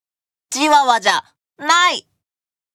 Chat Voice Files